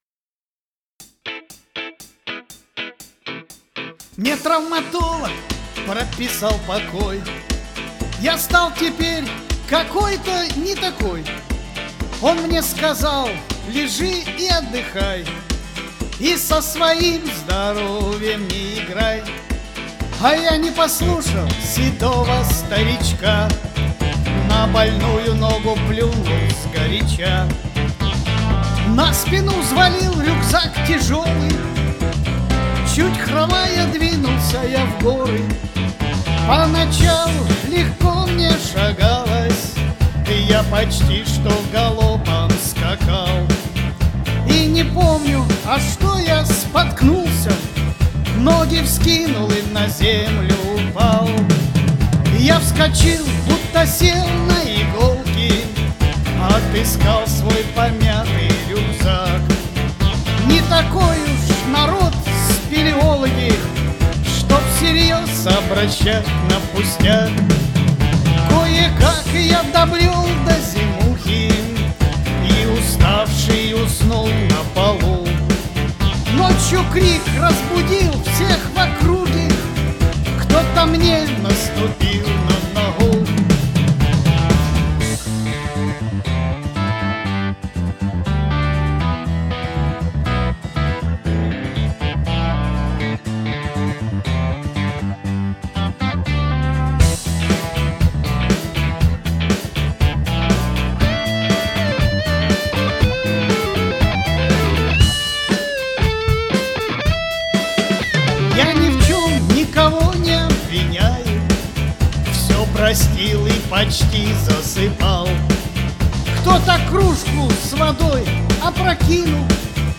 Шуточная песня написала в октябре 1989 года.